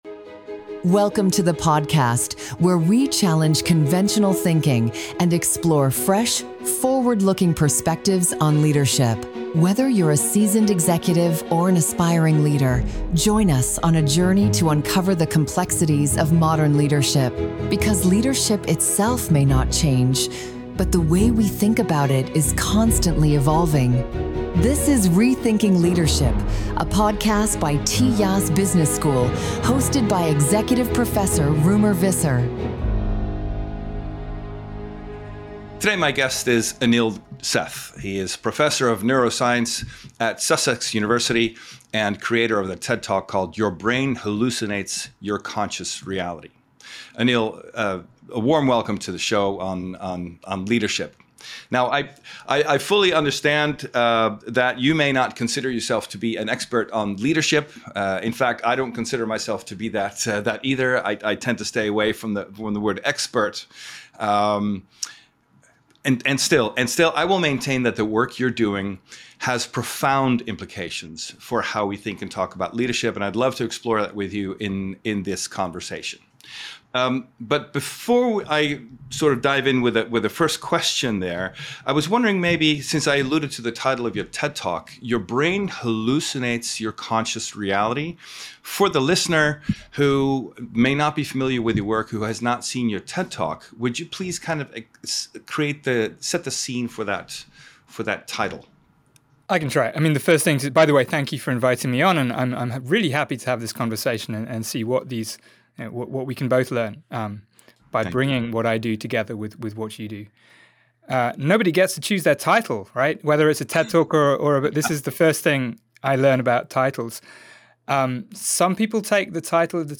Luister naar inspirerende gesprekken met thought leaders op het gebied van leiderschap en ga met ons mee op onderzoek om de complexiteiten van modern leiderschap te doorgronden.